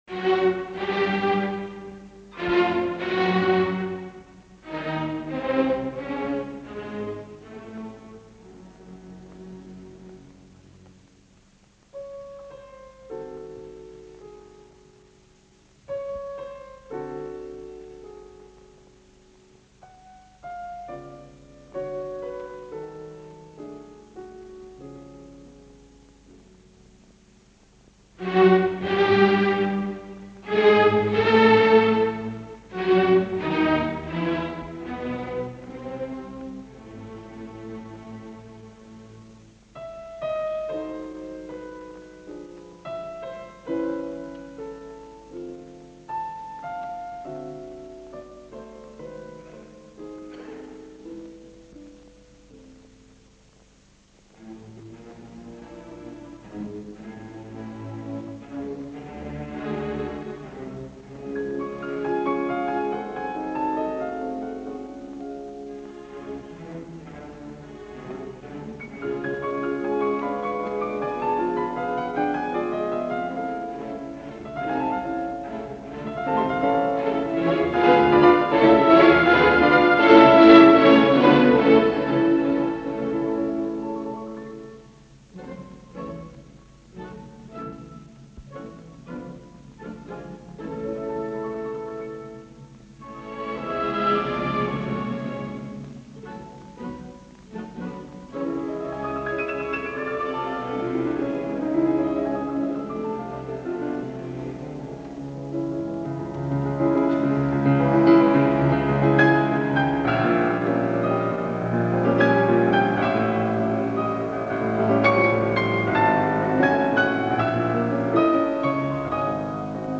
Franck – Symphonic variations Concertgebouw Orchestra Amsterdam
Live recording Soloists: Walter Gieseking, piano